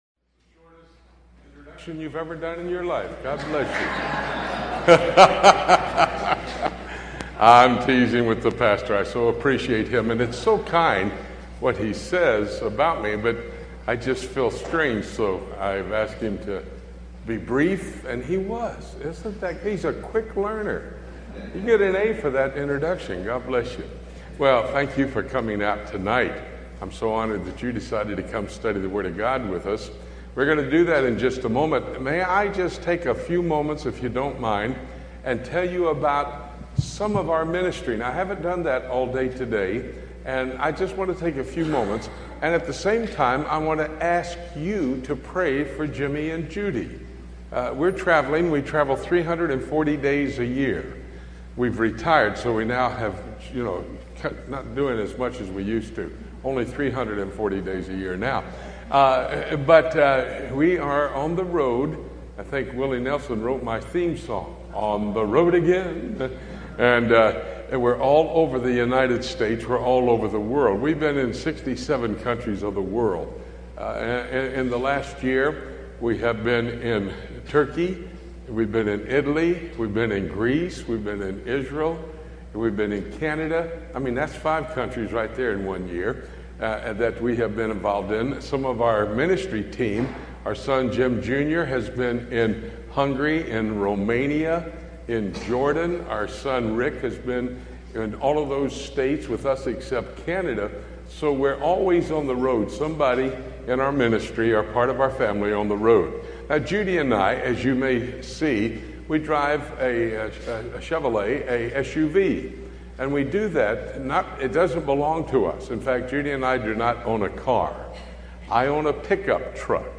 Sermons Archive | New Rocky Creek Baptist Church